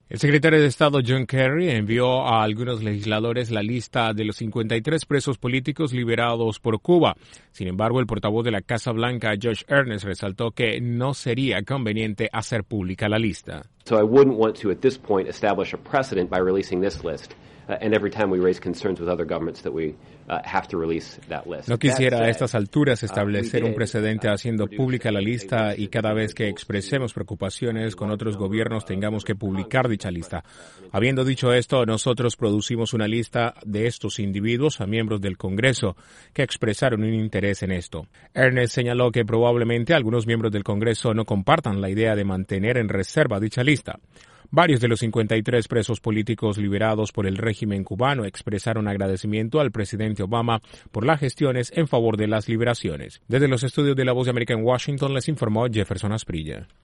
La Casa Blanca informó que no ve conveniente publicar la lista de los 53 presos políticos liberados por Cuba en el marco del proceso de normalización de relaciones de Estados Unidos con la Isla. Desde la Voz de América en Washington informa